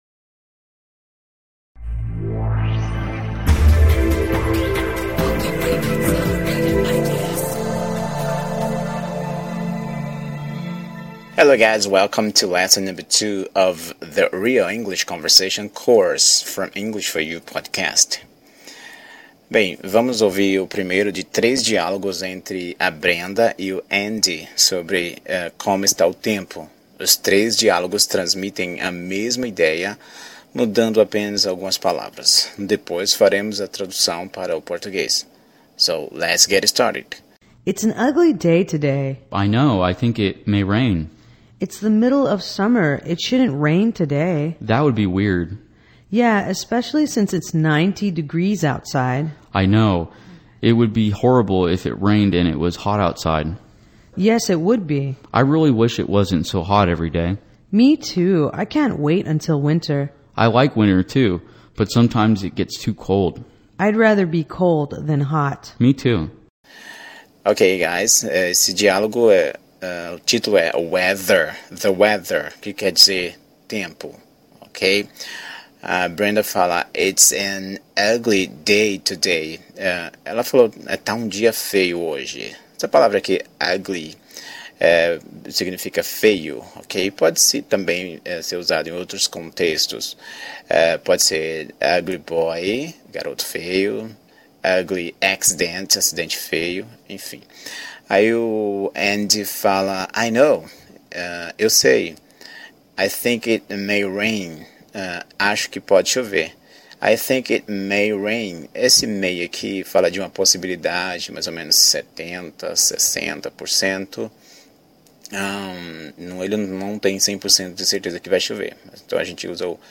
WEATHER, CONVERSATION 01